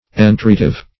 Search Result for " entreative" : The Collaborative International Dictionary of English v.0.48: Entreative \En*treat"ive\, a. Used in entreaty; pleading.